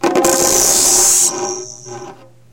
鼓上跳动的磁铁 " 鼓上跳动的磁铁013
描述：通过把磁铁一起扔到鼓上和空中而发出的声音。用iaudio u2 mp3录音机录制。磁铁被扔到汤姆鼓、康加鼓、手鼓、邦戈鼓上，并被扔到空气中与自己对抗。
标签： 弹跳 maganent-噪声 敲击
声道立体声